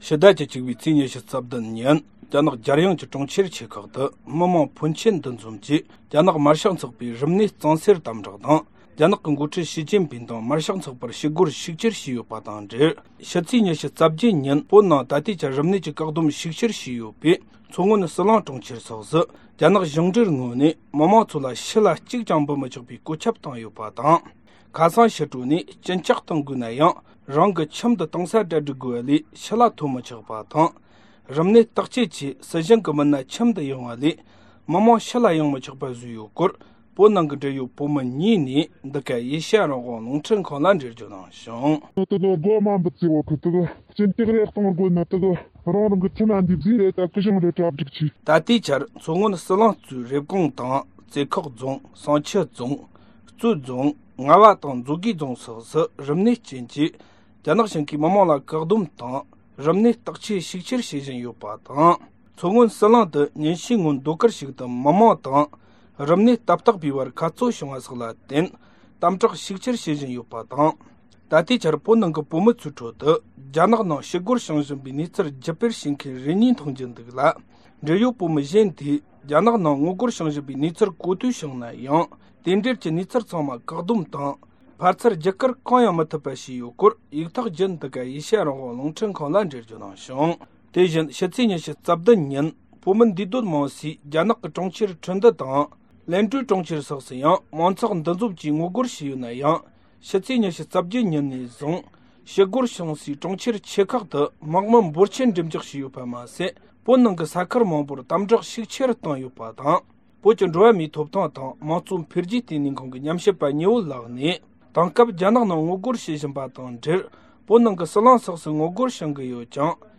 སྒྲ་ལྡན་གསར་འགྱུར། སྒྲ་ཕབ་ལེན།
ཕྱི་ཟླ་༡༡ཚེས་༢༧ཉིན་རྒྱ་ནག་རྒྱལ་ཡོངས་ཀྱི་གྲོང་ཁྱེར་ཆེ་ཁག་ཏུ་མི་མང་ཕོན་ཆེན་འདུ་འཛོམས་ཀྱིས་རྒྱ་ནག་དམར་ཤོག་ཚོགས་པའི་རིམས་ནད་གཙང་སེལ་དམ་དྲགས་དང་། རྒྱ་ནག་གི་མགོ་ཁྲིད་ཞི་ཅིན་ཕིན་དང་དམར་ཤོག་ཚོགས་པར་ཞི་རྒོལ་ཤུགས་ཆེར་བྱས་ཡོད་པ་དང་འབྲེལ། ཕྱི་ཚེས་༢༨་ཉིན་ནས་བཟུང་བོད་ནང་ད་ལྟའི་ཆར་རིམས་ནད་ཀྱི་བཀག་བསྡོམས་ཤུགས་ཆེར་བྱས་ཡོད་པའི་མཚོ་སྔོན་ཟི་ལིང་གྲོང་ཁྱེར་སོགས་སུ། རྒྱ་ནག་གཞུང་འབྲེལ་ངོས་ནས་མི་མང་ཚོ་ཕྱི་ལ་གཅིག་ཀྱང་འབུད་མི་ཆོག་པའི་བཀོད་ཁྱབ་བཏང་ཡོད་པ་དང་། ཁ་སང་ཕྱི་དྲོ་ནས་གཅིན་སྐྱག་གཏོང་དགོས་ན་རང་གི་ཁྱིམ་དུ་གཏོང་ས་གྲ་སྒྲིག་དགོས་པ་ལས་ཕྱི་ལ་ཐོན་མི་ཆོག་པ་དང་། རིམས་ནད་བརྟག་དཔྱད་ཆེད་སྲིད་གཞུང་གི་མི་སྣ་ཁྱིམ་དུ་ཡོང་བ་ལས་མི་མང་ཕྱི་ལ་ཡོང་མི་ཆོག་པ་བཟོས་ཡོད་སྐོར། བོད་ནང་གི་འབྲེལ་ཡོད་བོད་མི་གཉིས་ནས་འགྲེལ་བརྗོད་གནང་བྱུང་།